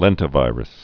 (lĕntĭ-vīrəs)